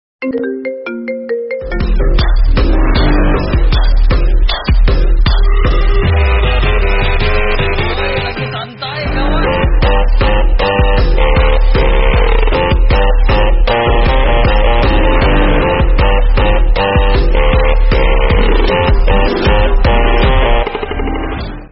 Kategori: Nada dering